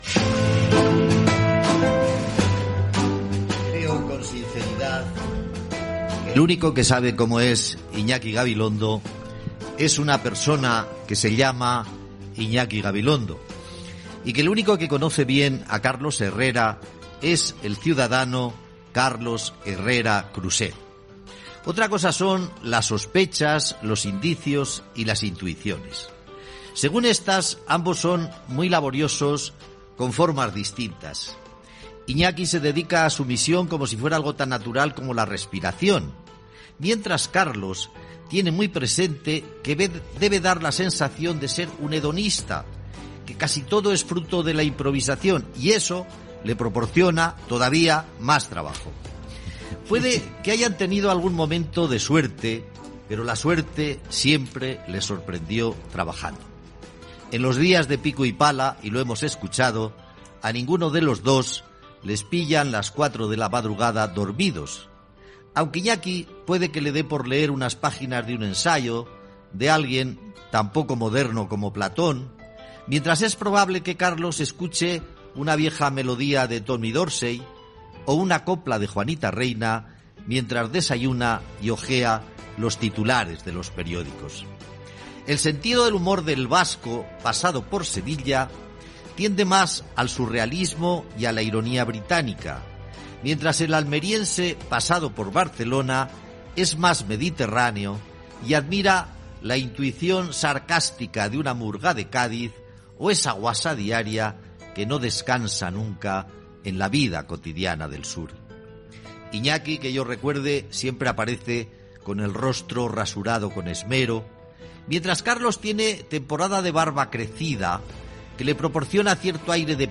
Info-entreteniment
FM